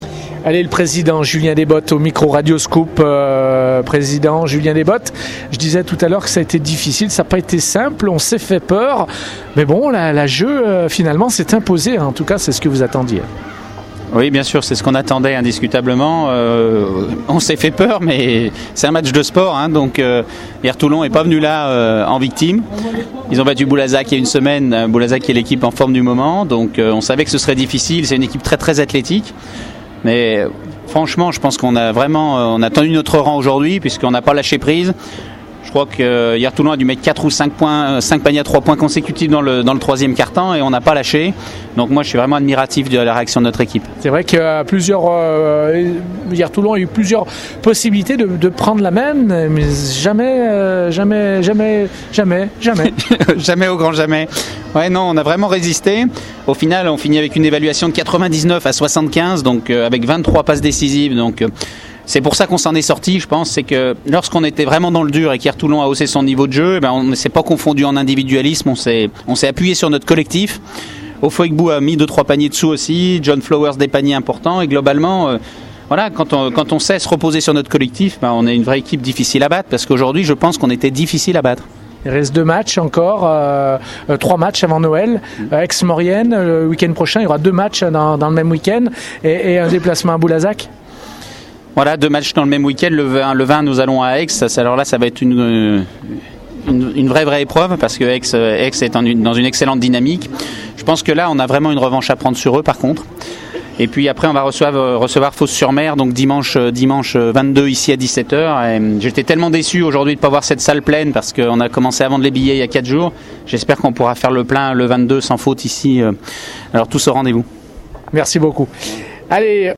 On écoute les réactions d’après-match au micro de Radio Scoop.